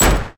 Gunshot1.ogg